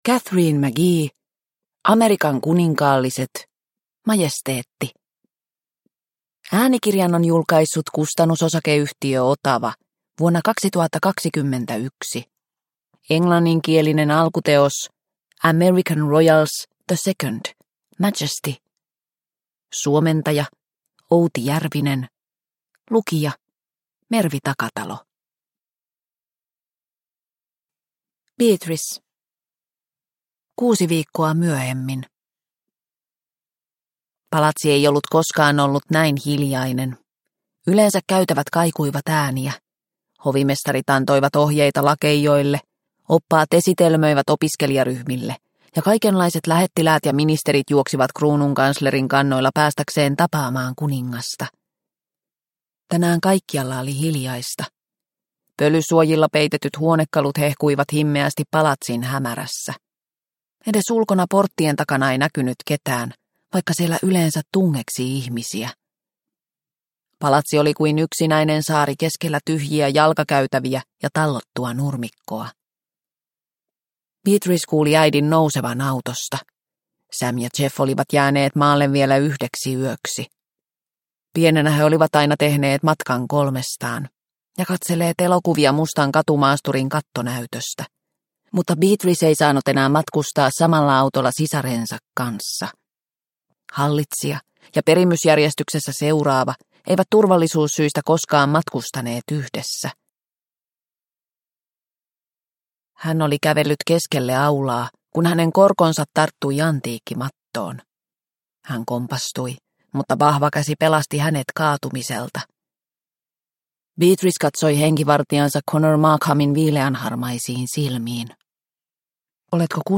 Amerikan kuninkaalliset - Majesteetti – Ljudbok – Laddas ner